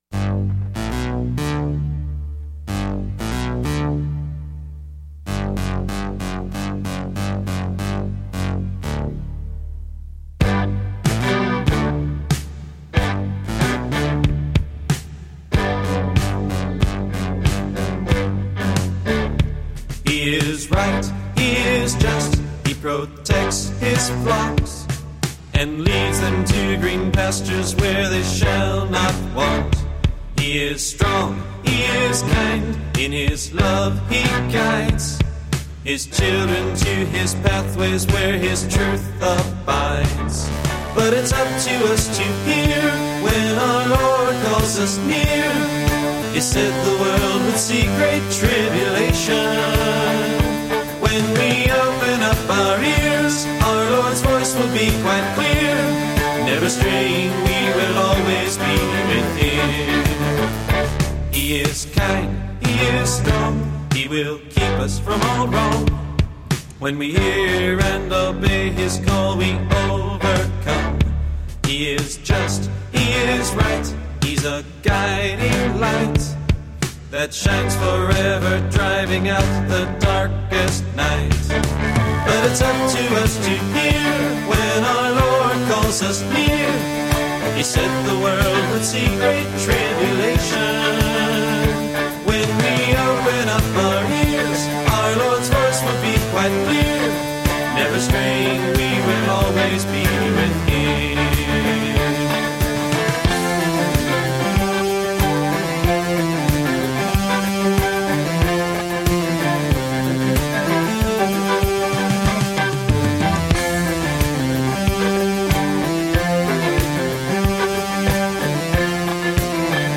Piano
Electric & acoustic guitars
Bass guitar
Percussion
Trumpet
English horn, saxophone and clarinet
Synthesizer
Background vocals